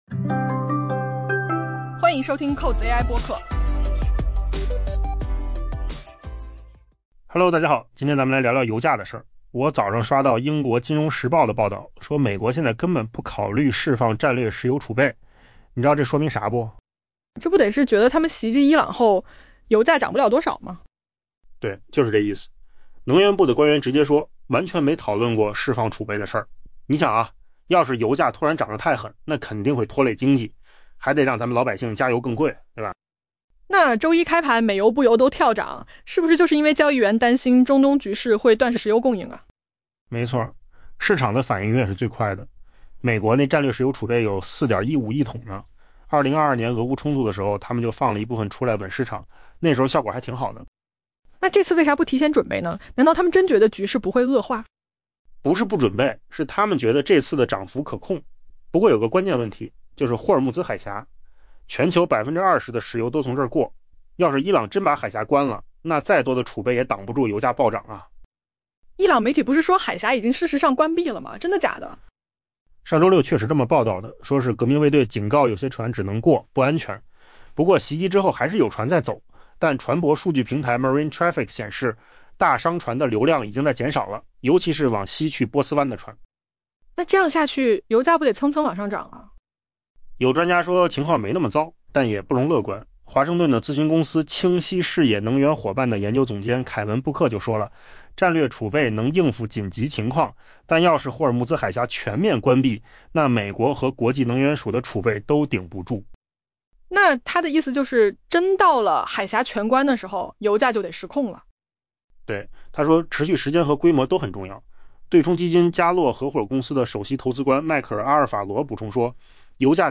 AI 播客：换个方式听新闻 下载 mp3 音频由扣子空间生成 据英国 《金融时报》 报道，美国目前不考虑释放战略石油储备，这表明华盛顿方面认为， 其袭击伊朗后可能出现的油价上涨幅度将较为有限。